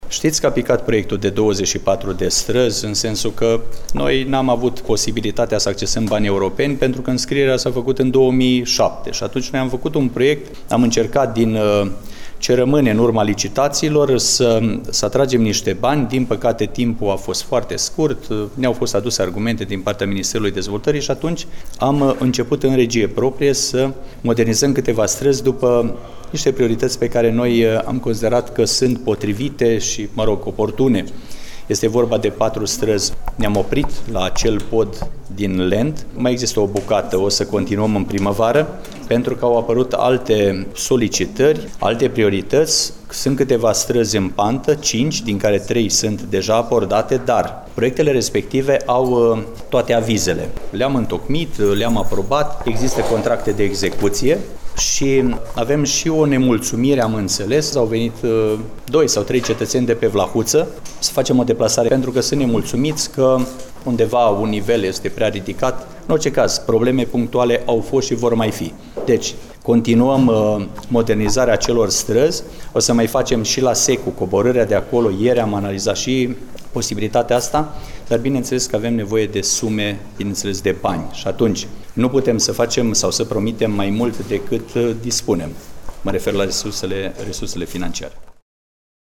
Ascultați declarația primarului Mihai Stepanescu